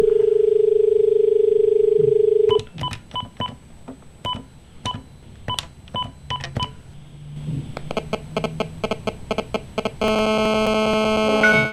mobile beep1
beep mobile phone register speaker sound effect free sound royalty free Voices